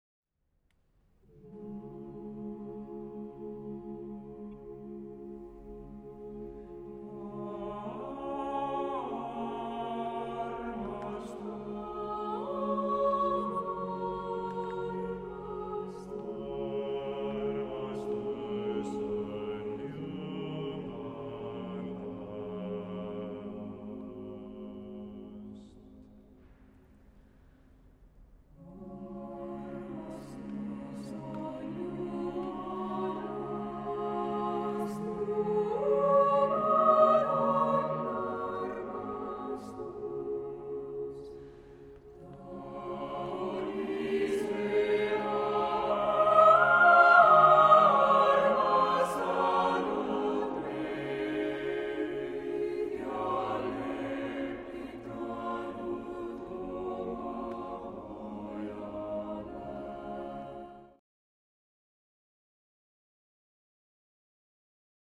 Vuonna 1992 sävelletty sekakuorolaulu